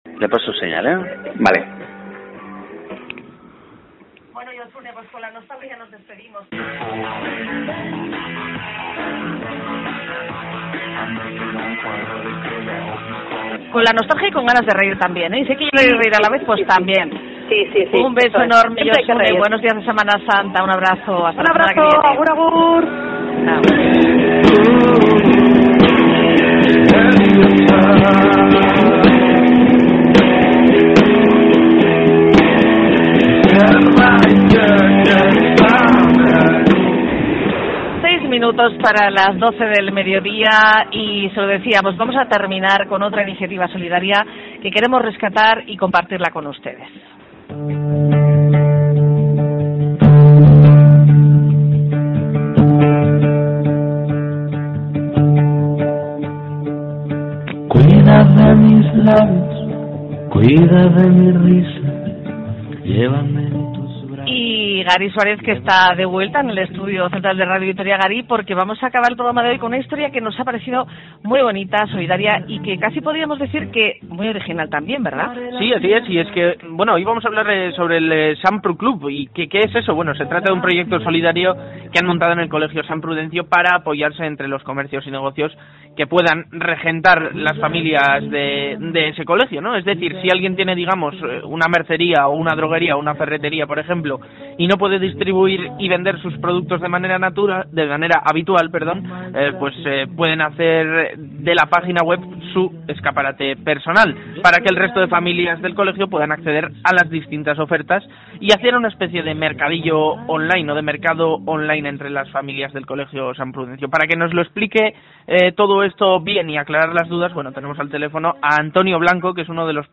Entrevista Radio Vitoria Comparte esta entrada en tus redes sociales Facebook Twitter Print Email Linkedin Whatsapp